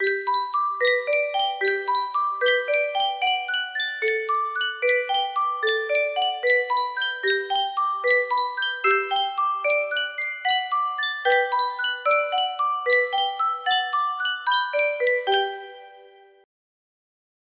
Sankyo Music Box - Invitation to the Dance AX music box melody
Full range 60